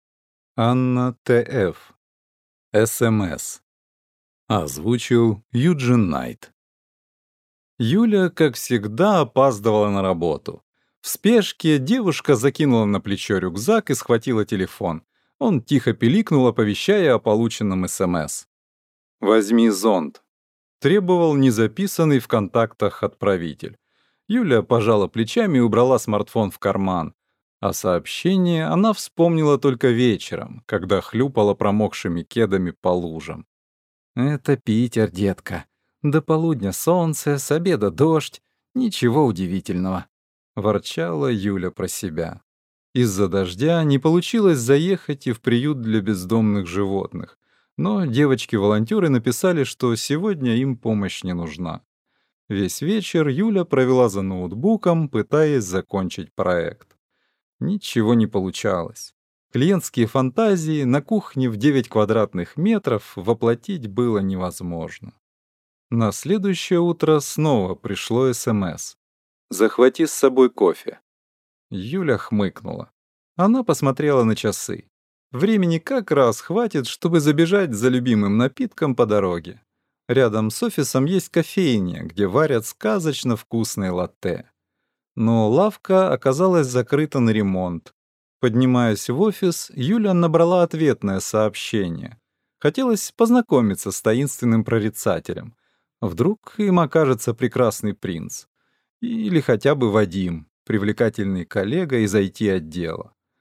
Аудиокнига SMS | Библиотека аудиокниг